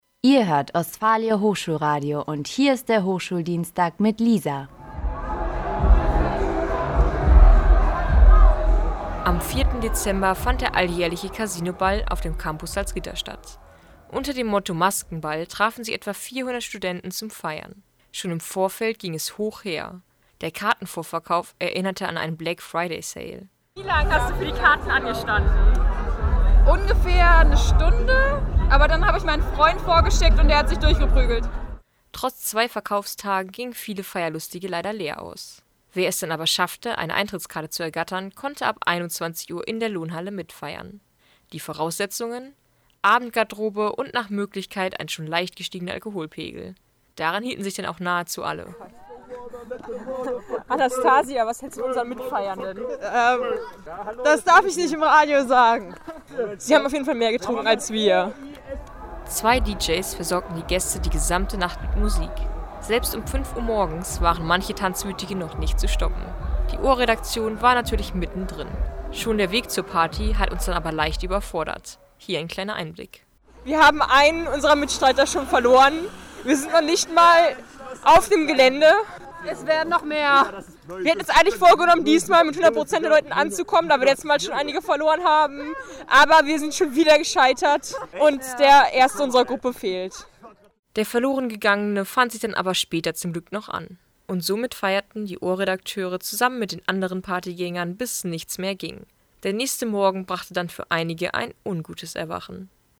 Auch wir waren mittendrin und haben unser Mikrofon gezückt. Die Stimmung war da und der Alkohol floß.